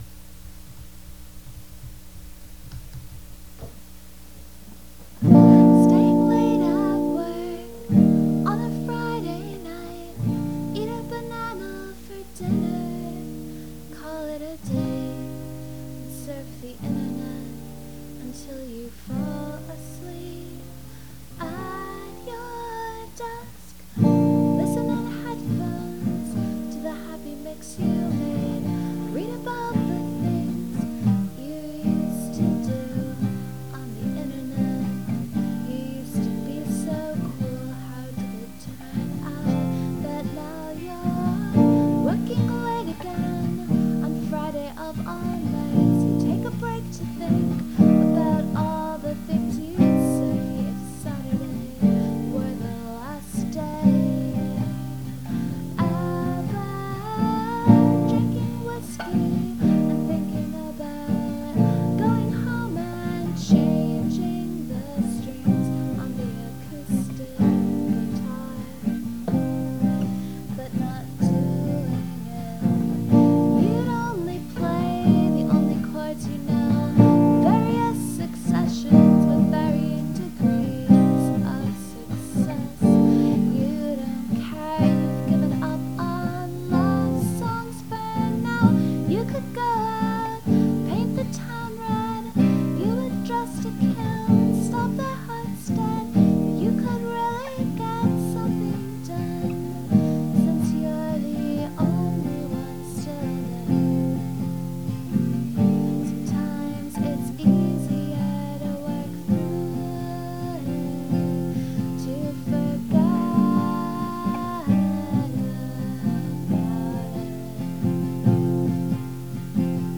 (all with capo on third fret)